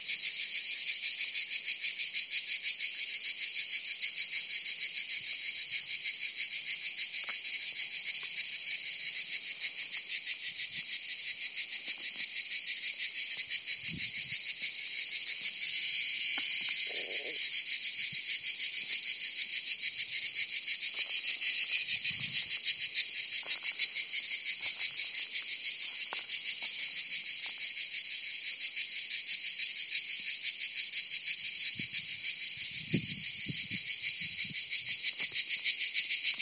描述：蝉在佛罗里达后院在一个夏天晚上
Tag: 性质 现场记录 夏天 昆虫